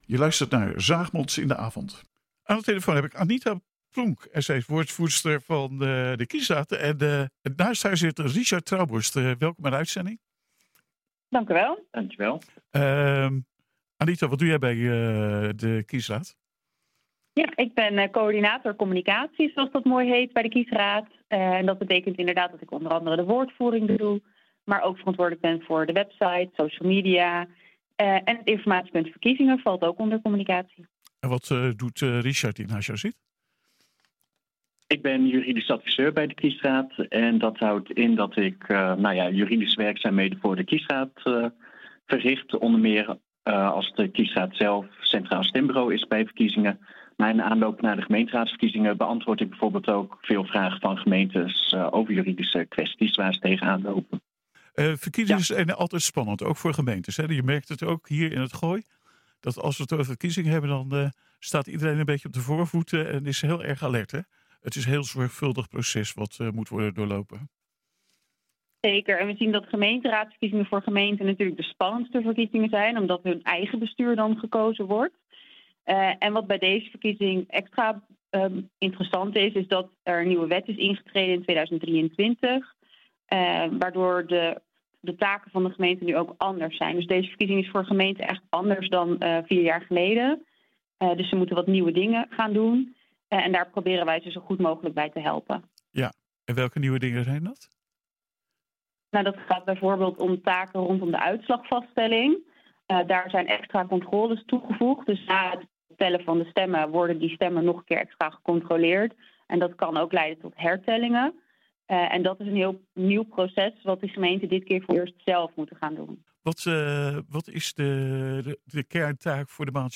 Hoe lopen de voorbereidingen en wat gebeurt er achter de schermen. We praten met woordvoerders en deskundigen van de Centrale Kiesraad in Den Haag .